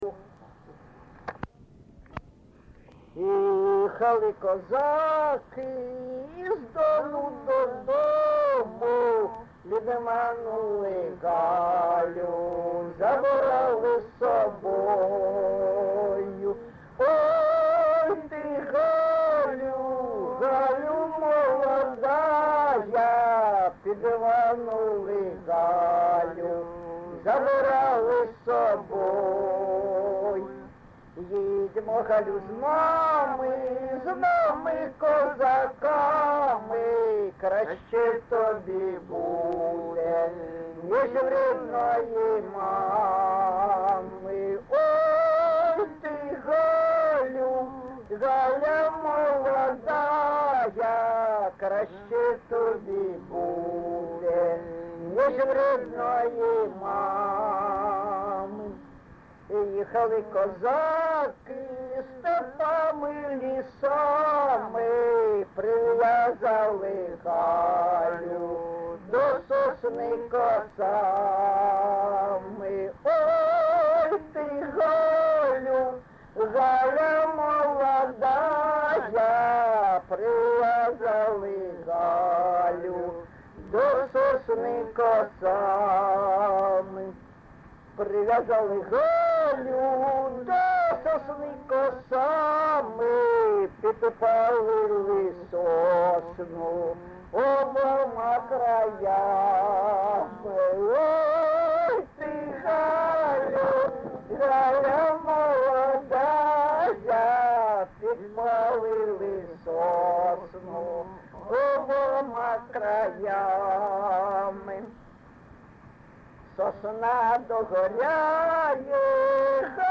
ЖанрПісні з особистого та родинного життя, Козацькі
Місце записум. Дебальцеве, Горлівський район, Донецька обл., Україна, Слобожанщина